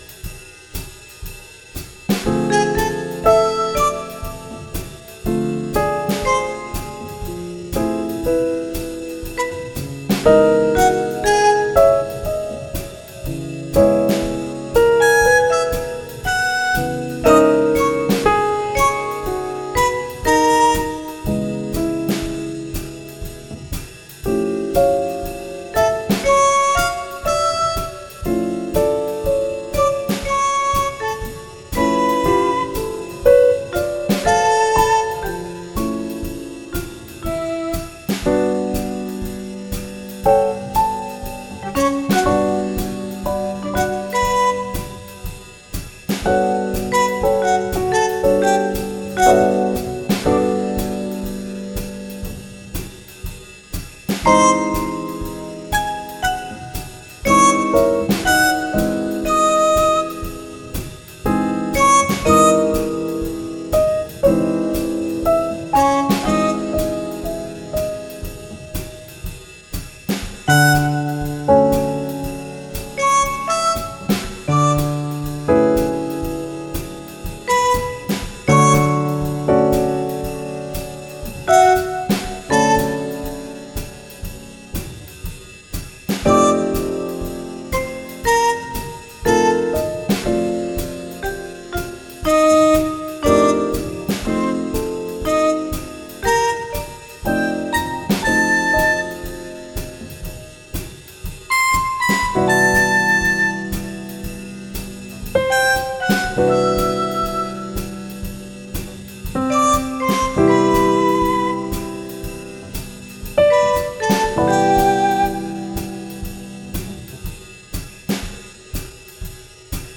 jazz mastered june 3, 2021
jazz_mastered_2.mp3